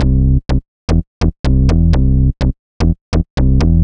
cch_bass_loop_word_125_Cm.wav